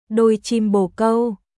đôi chim bồ câu鳩のつがいドイ チム ボー カウ